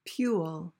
PRONUNCIATION: (pyool) MEANING: verb intr.: To whimper or whine.